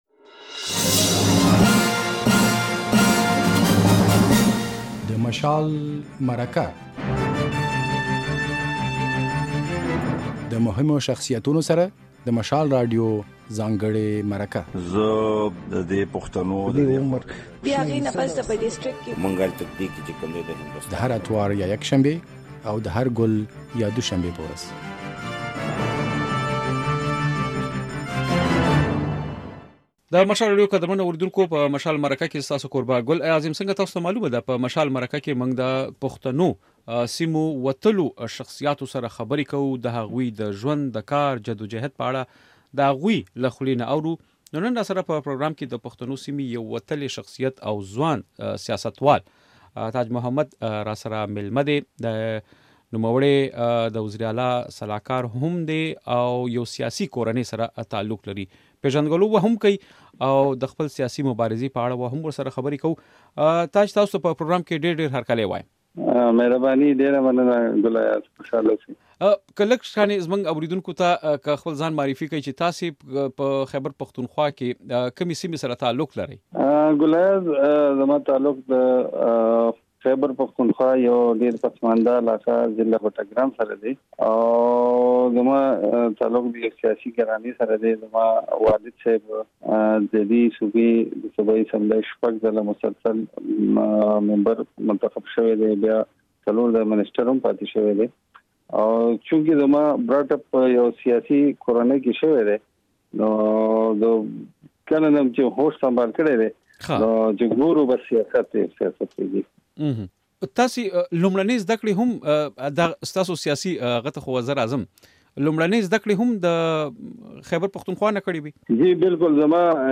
دا ځل په "مشال مرکه" کې د خيبر پښتونخوا د اعلا وزير ځانګړی استازی تاج محمد مېلمه دي.